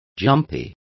Complete with pronunciation of the translation of jumpiest.